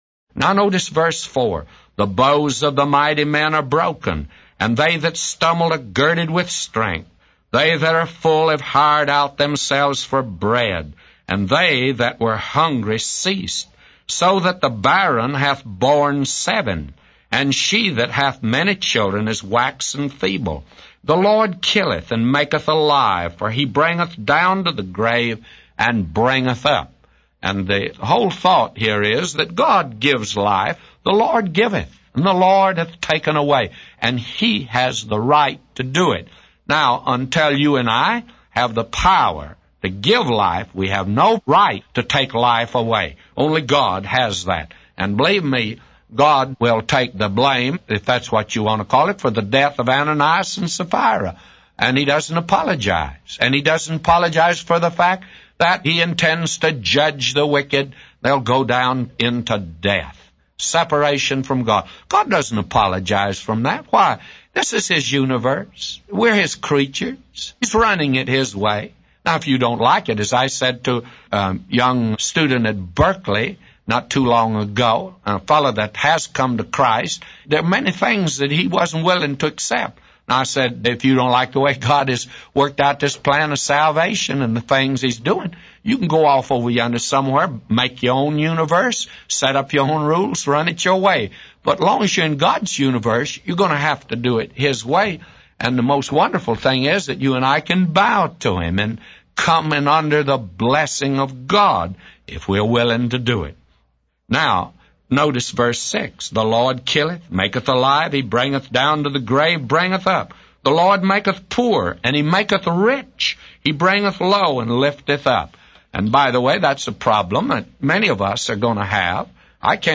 A Commentary By J Vernon MCgee For 1 Samuel 2:4-999